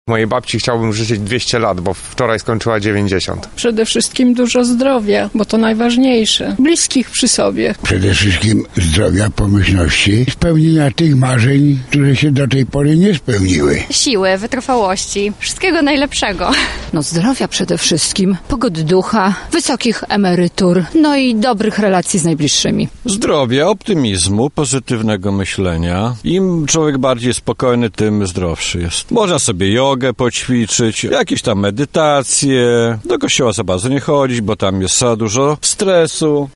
Zapytaliśmy przechodniów, czego chcą życzyć najstarszym mieszkańcom Lublina:
sonda